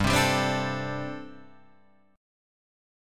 Gsus2sus4 chord